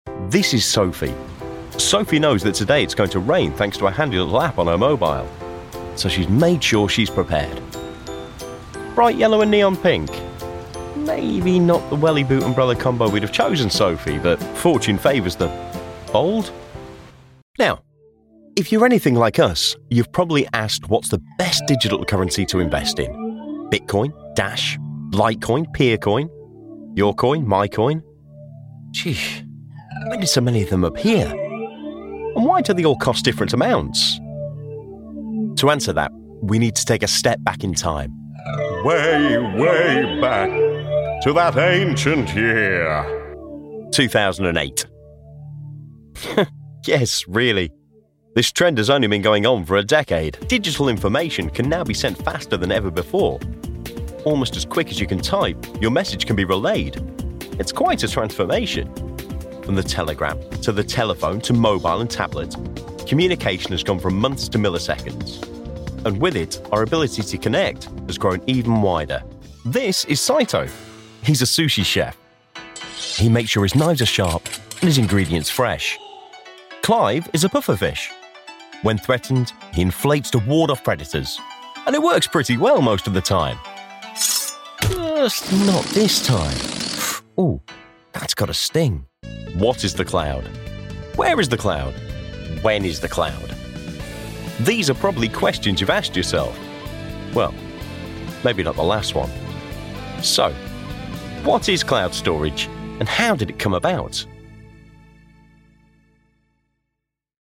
Male
Warm, Assured, Authoritative, Bright, Bubbly, Character, Cheeky, Confident, Cool, Corporate, Deep, Gravitas, Sarcastic, Smooth, Soft, Wacky, Witty, Engaging, Friendly, Natural, Reassuring, Versatile
British English, RP, Northern, Estuary, General Scottish, Genereric Irish
Microphone: Neumann U87ai, Sennheiser 416,
Audio equipment: Audient ID22, Studiobricks Sound Booth